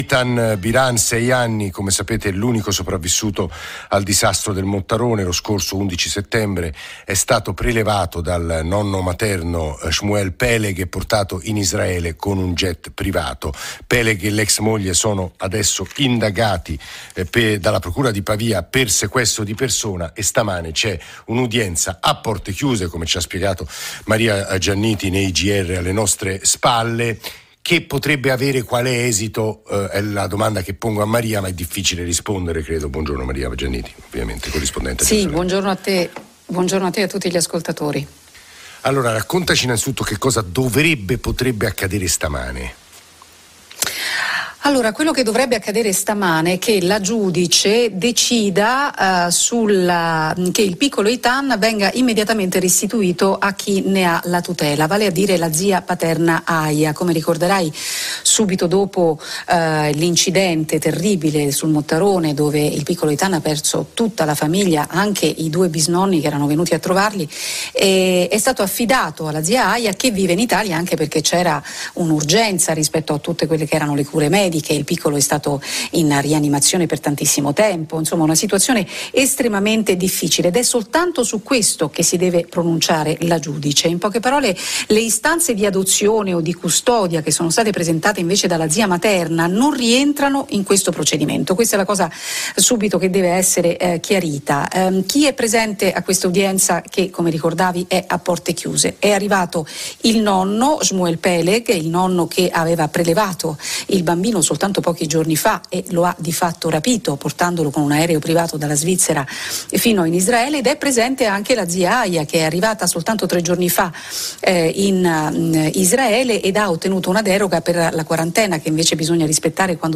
Intervista a Radio Anch'io (RADIO 1 RAI)